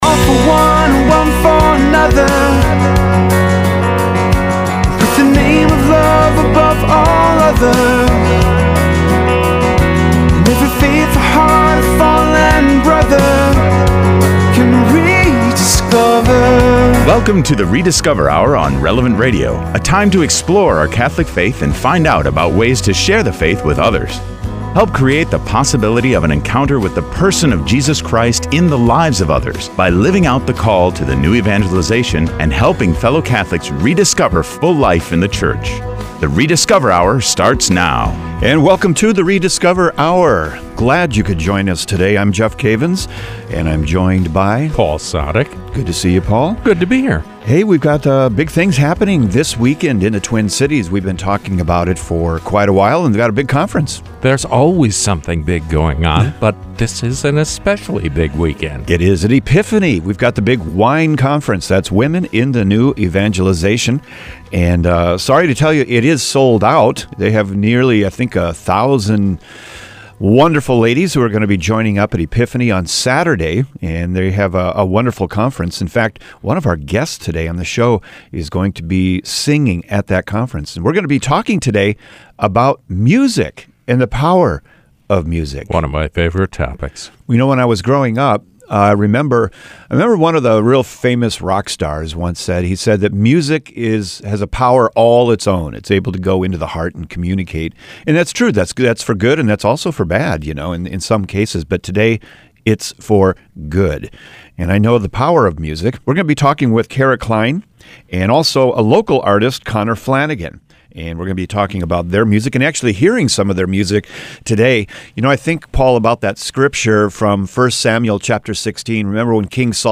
En el programa «The Rediscover: Hour», entrevistamos a músicos católicos sobre «El poder de la música».